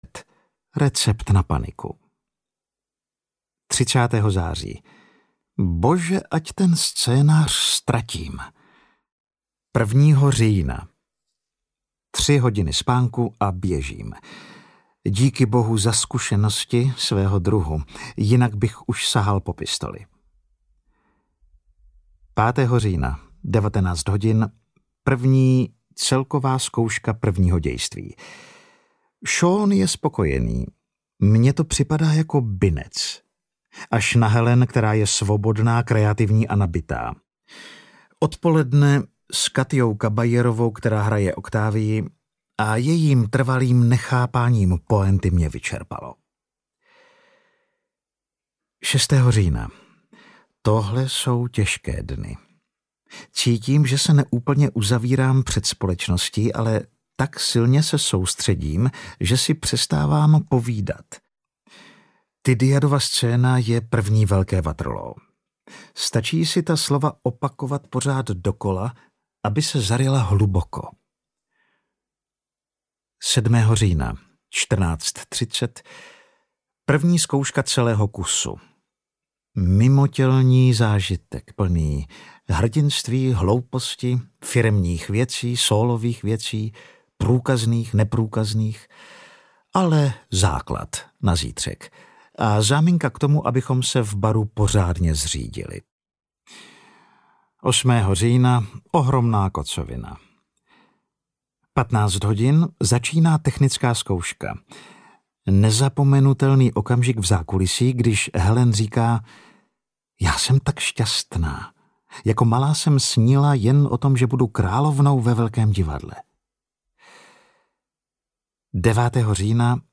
Audio knihaAlan Rickman: Deníky
Ukázka z knihy